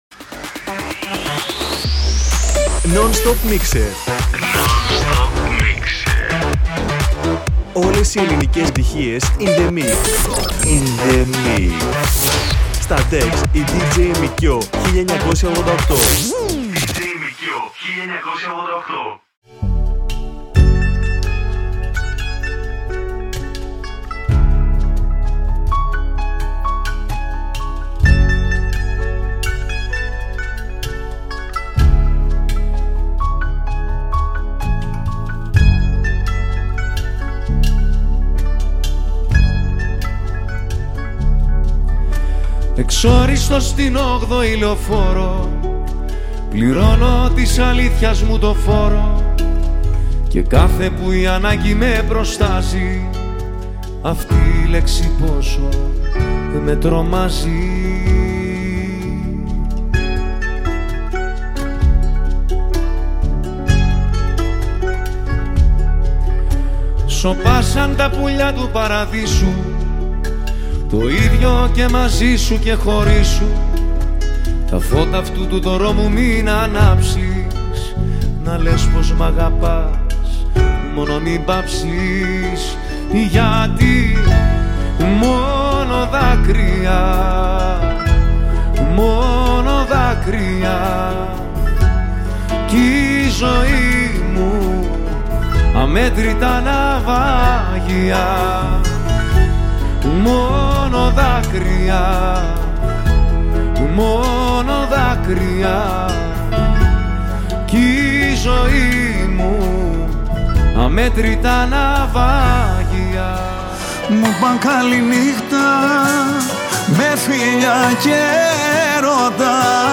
GREEKMUSIC